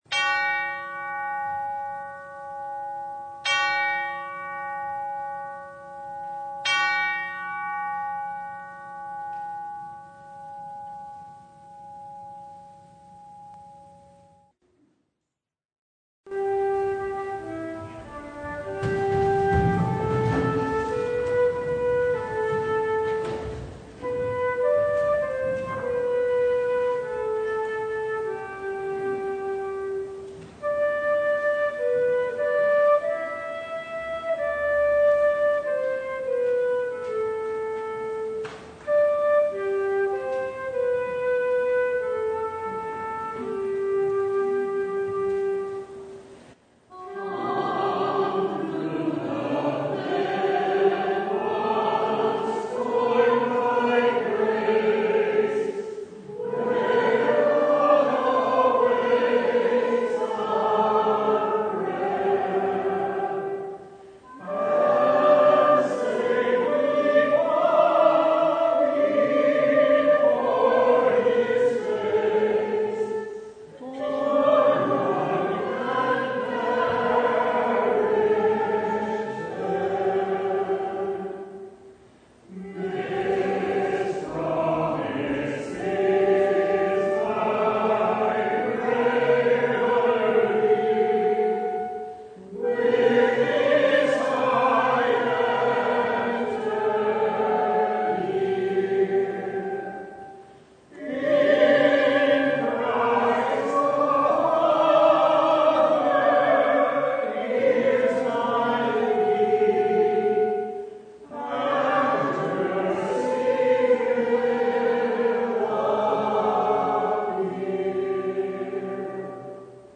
Service Type: Sunday